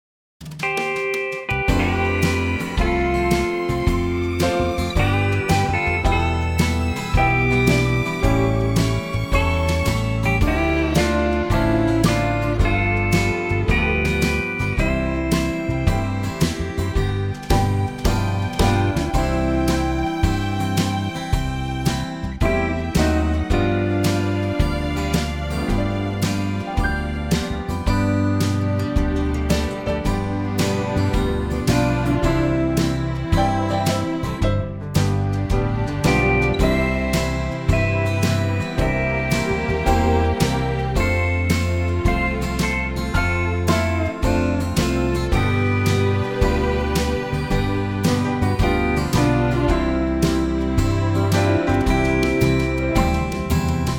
Unique Backing Tracks
key - C - vocal range - B to D
Here's a nice country arrangement as well!